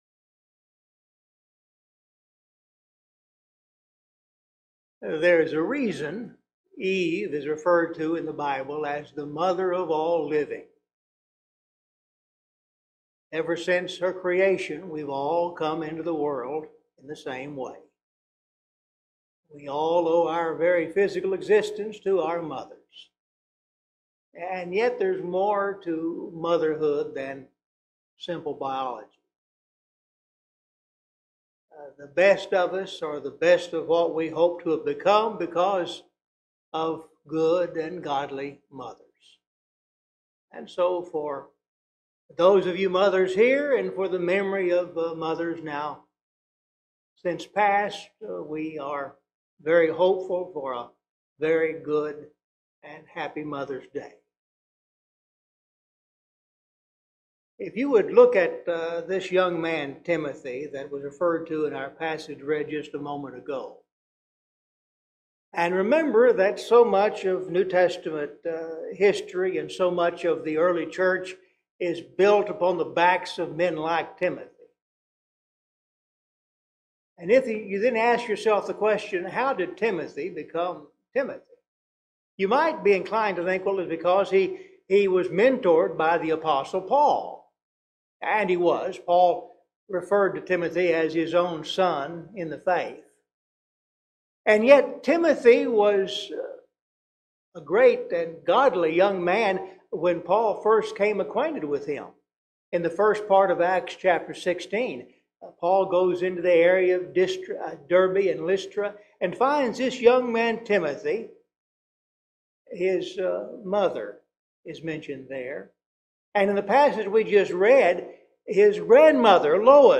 Scripture Reading